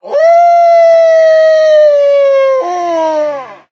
sounds / mob / wolf / howl2.ogg
howl2.ogg